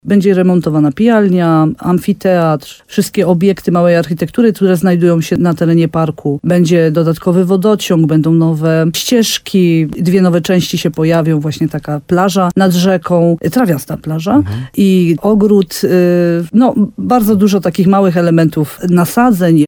– Wybraliśmy dwóch wykonawców. Jeden wykona prace dotyczące nasadzeń i zieleni, a drugi całą części budowlaną – powiedziała radiu RDN Nowy Sącz wójt Ewa Garbowska-Góra.